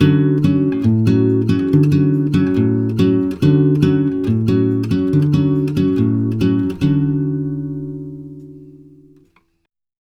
140GTR D7  1.wav